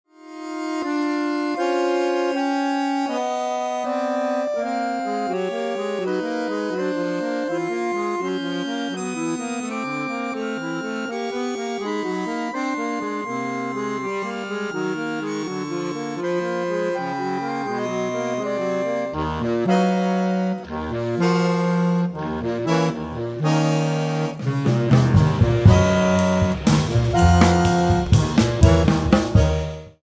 soprano sax, bassclarinet
accordion
double bass
drums